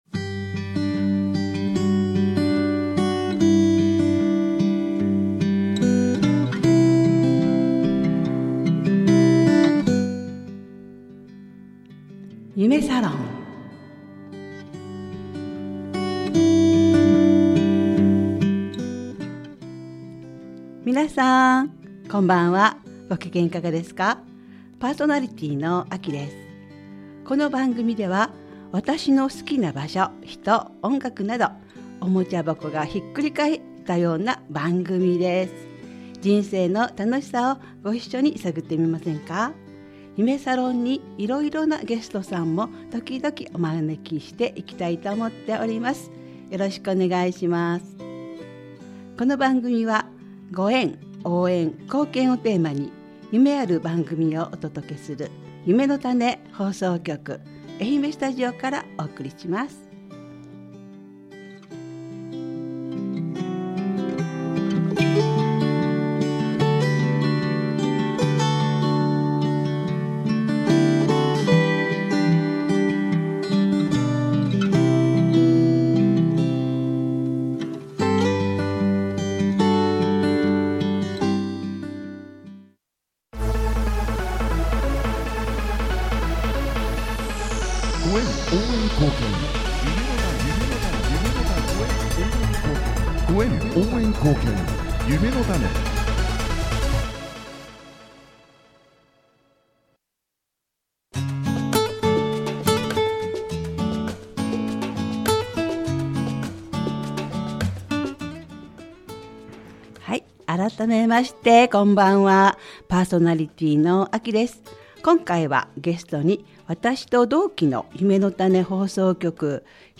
愛媛スタジオ